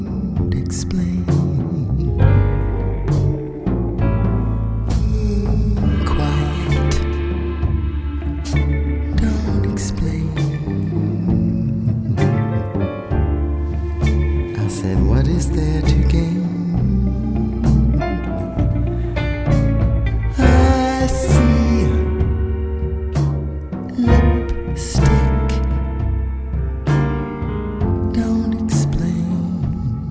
0 => "Jazz"